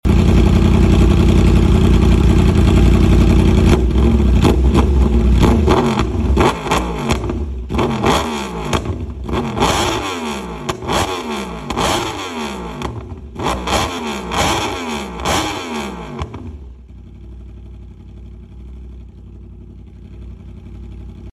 R1M With SC Project Full Sound Effects Free Download